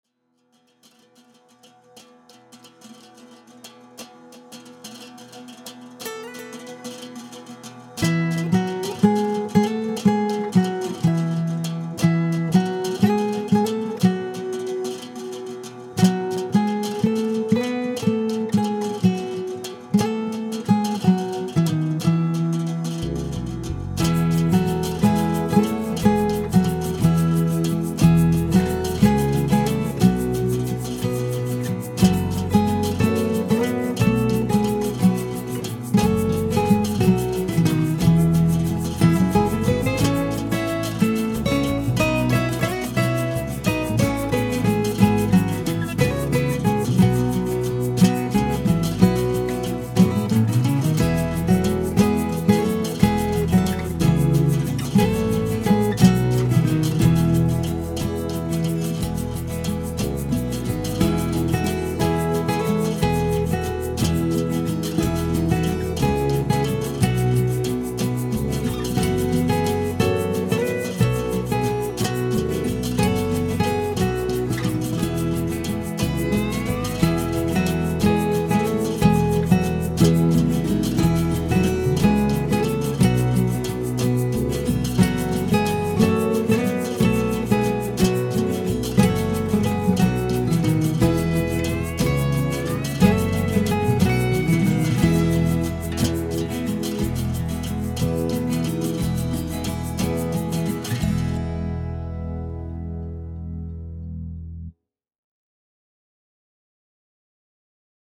If you don’t like all that noisy modern stuff, I have also done some acoustic pieces.